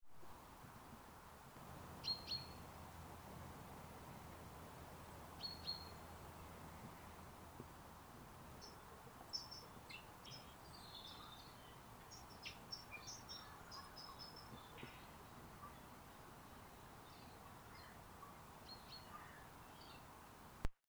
NatureDay3.wav